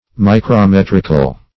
Search Result for " micrometrical" : The Collaborative International Dictionary of English v.0.48: Micrometric \Mi`cro*met"ric\, Micrometrical \Mi`cro*met"ric*al\, a. [Cf. F. microm['e]trique.]